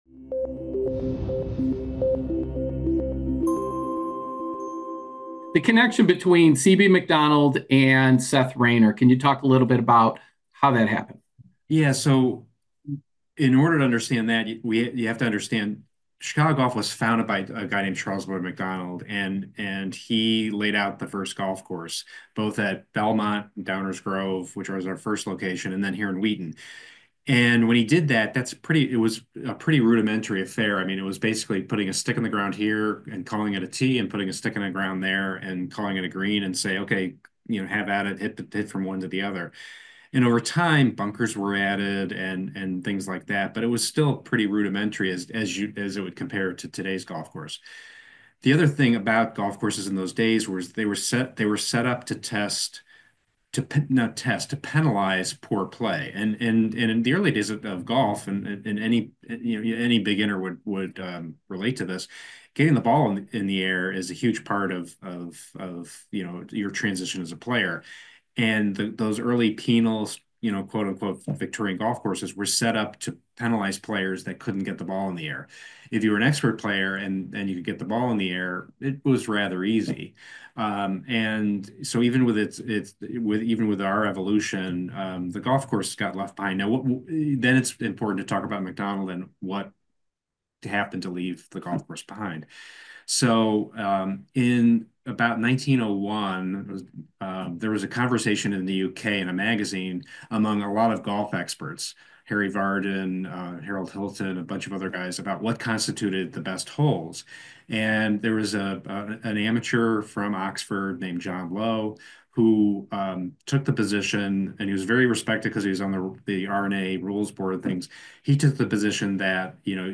The Chicago Golf Report Podcast brings the best of golf in Chicago to you featuring exclusive interviews with some of top newsmakers in the Chicagoland golf community.